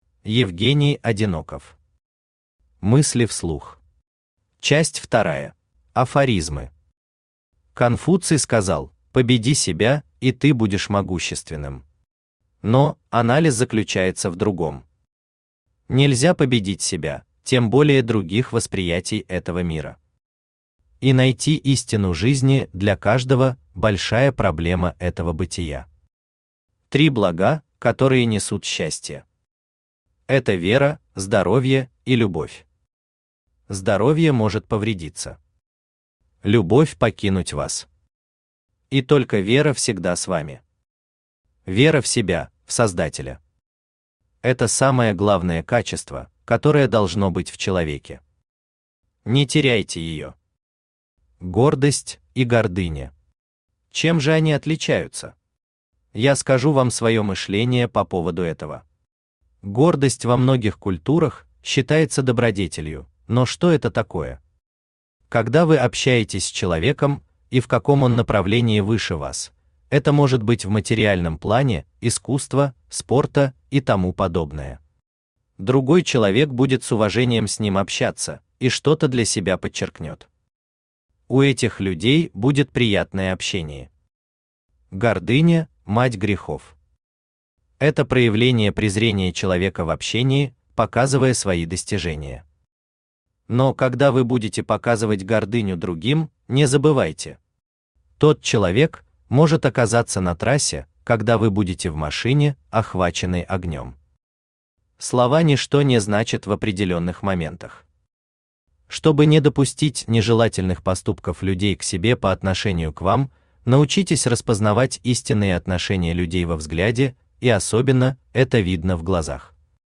Часть вторая Автор Евгений Одиноков Читает аудиокнигу Авточтец ЛитРес.